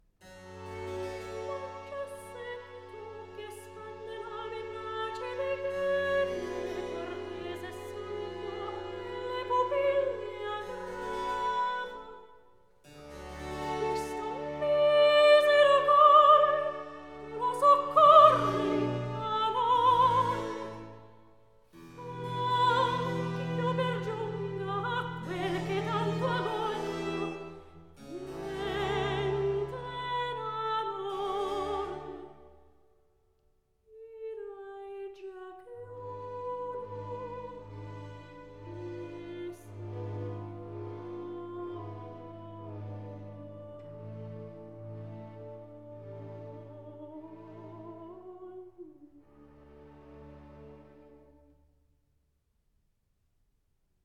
Accompagnato